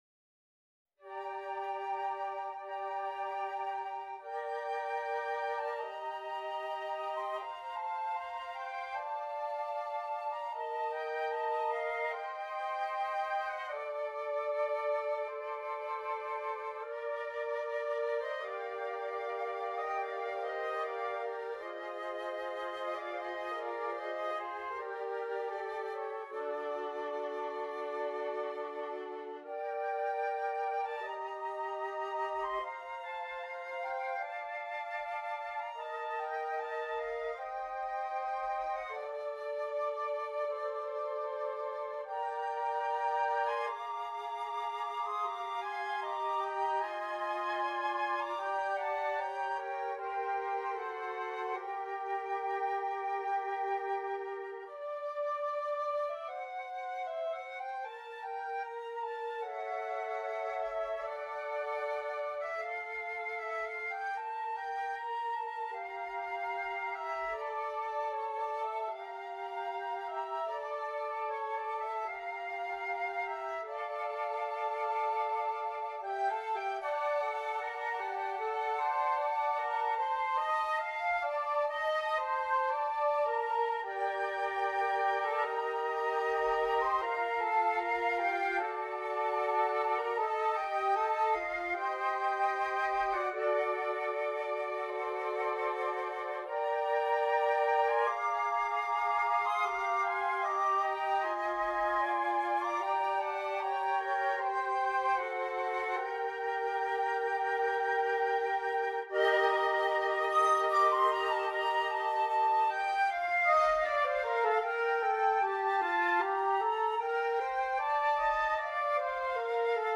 Voicing: Flute Quartet